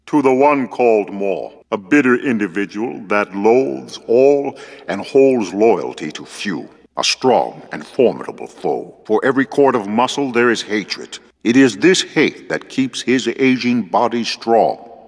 Qu Rahn as a Force ghost, to Kyle Katarn — (audio)